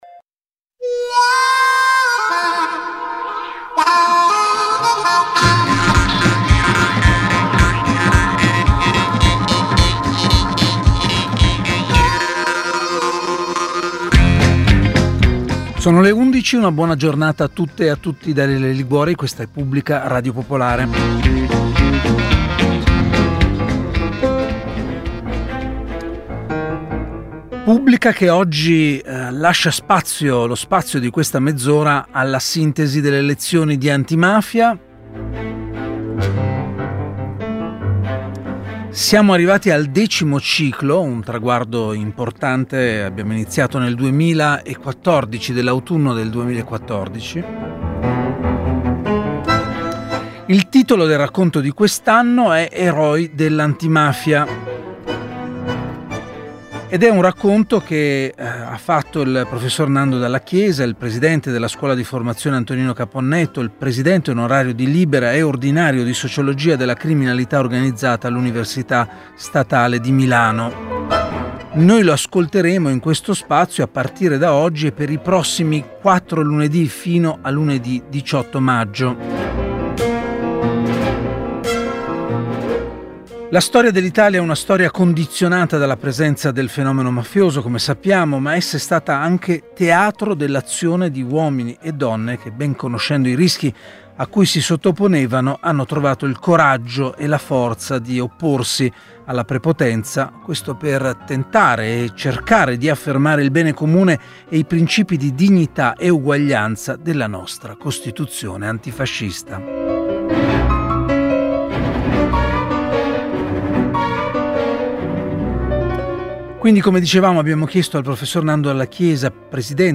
Prima lezione del nuovo ciclo di incontri (il decimo) ideato dalla Scuola di formazione “Antonino Caponnetto” e realizzato insieme a Radio...